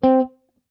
c_note.wav